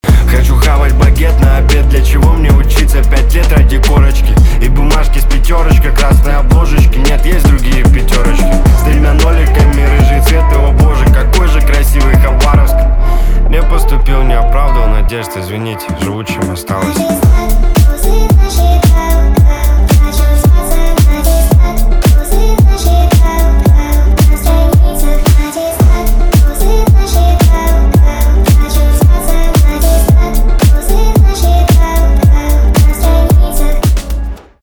поп
грустные
басы